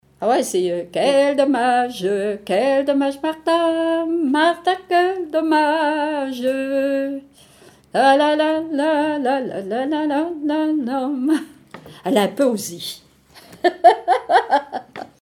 Genre laisse
Témoignages sur les chansons
Pièce musicale inédite